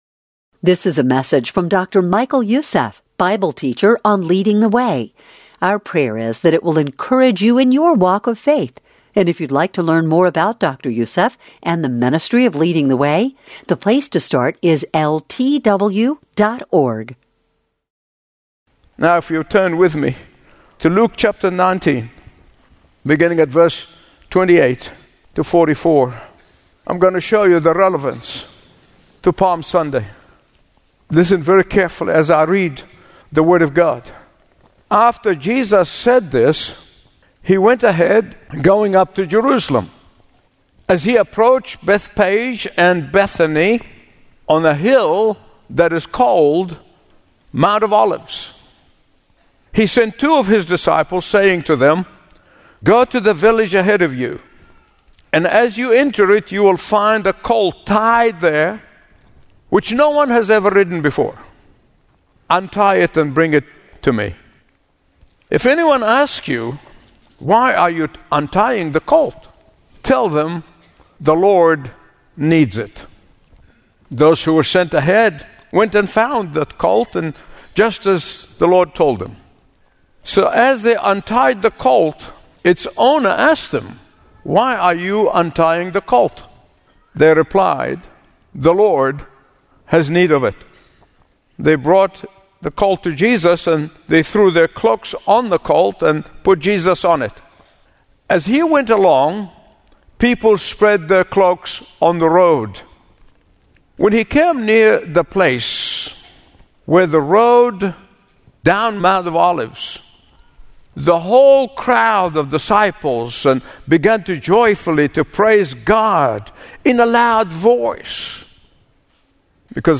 Discover a collection of impactful radio messages from Dr. Michael Youssef, including Palm Sunday: Discerning the Times.